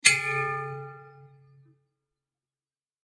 お寺の鰐口.mp3